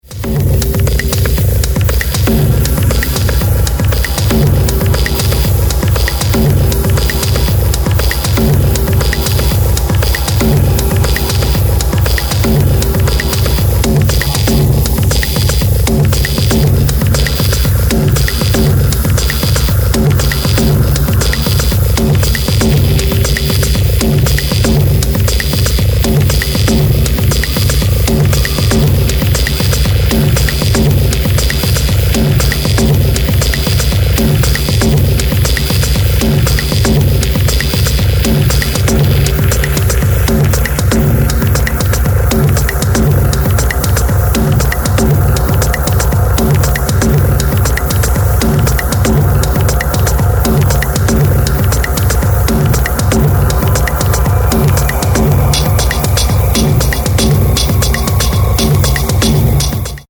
Electronix Techno